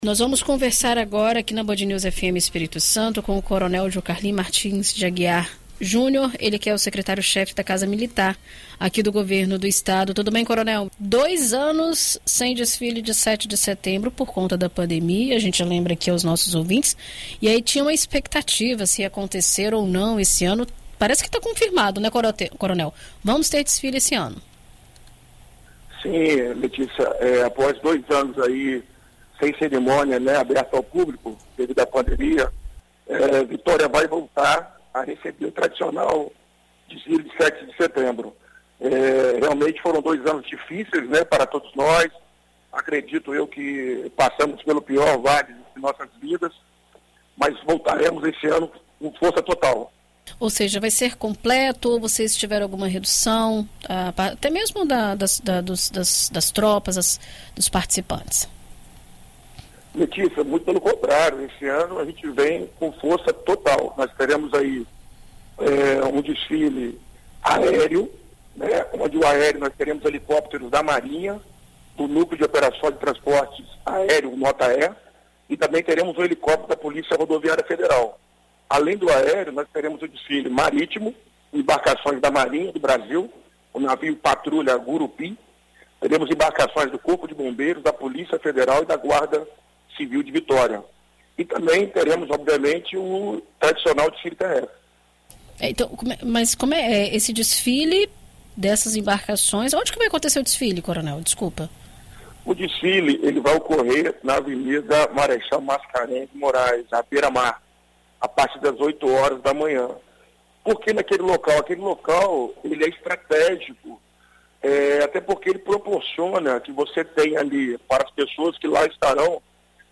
Em entrevista à BandNews FM Espírito Santo nesta terça-feira (30), o secretário-chefe da Casa Militar do Estado, coronel Jocarly Martins de Aguiar Júnior, detalha a organização do evento e como o desfile se conecta com os costumes e tradições presentes no país.